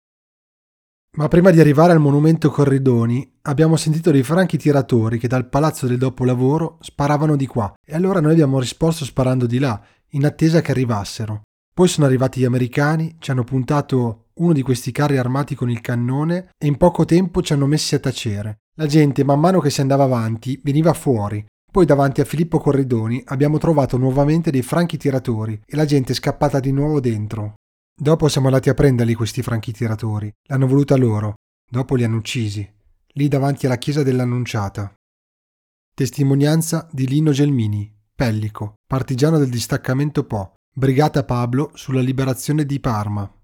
testimonianza-audio-cecchinaggio.wav